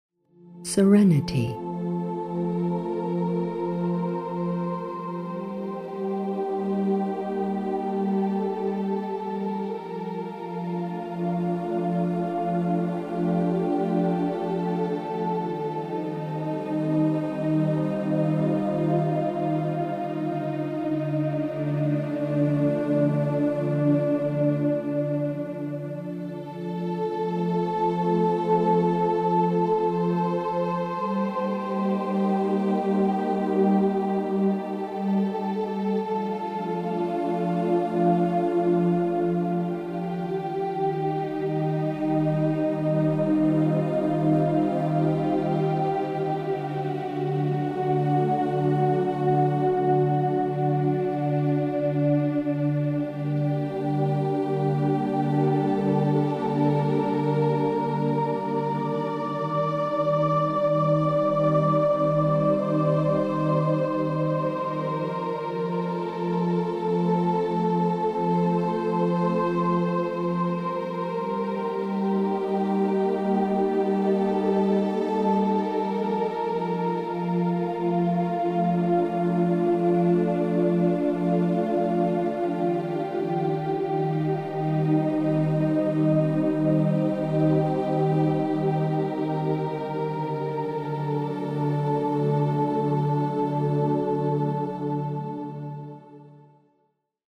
терапевтических музыку